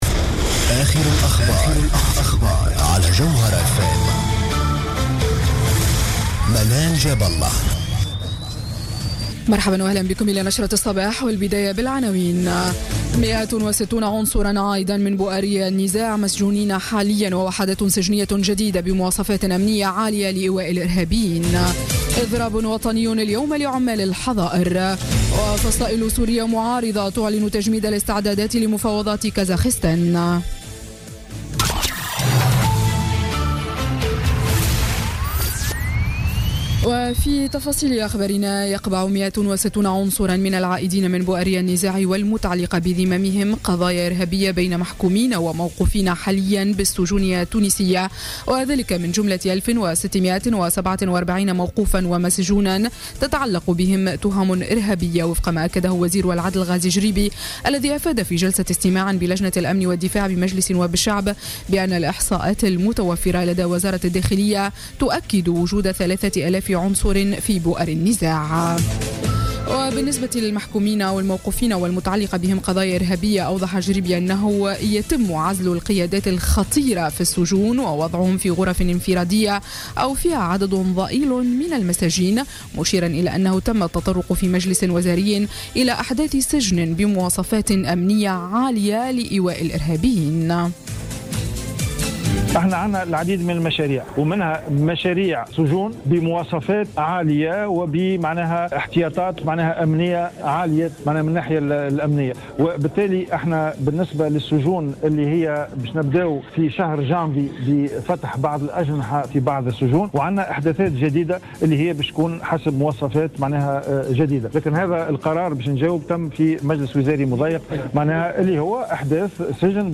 نشرة أخبار السابعة صباحا ليوم الثلاثاء 3 جانفي 2017